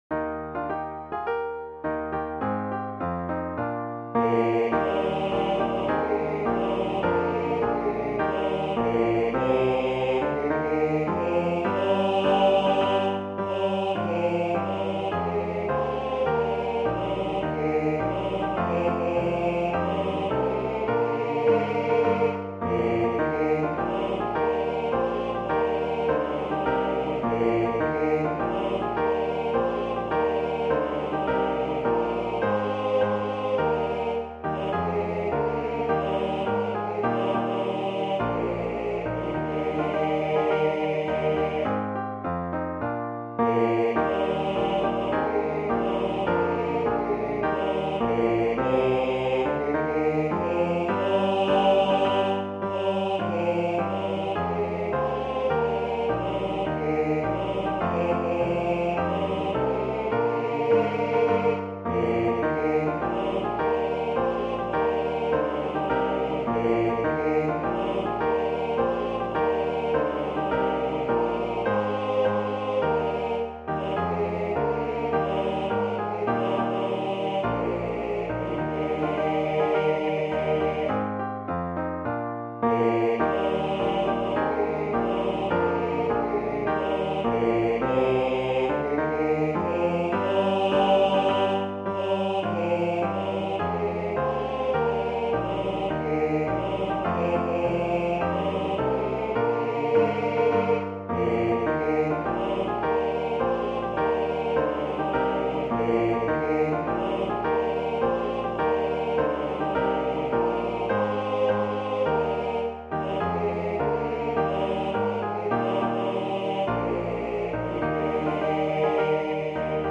Voicing/Instrumentation: TBB